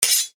sword-unsheathe2.wav